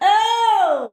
OOH.wav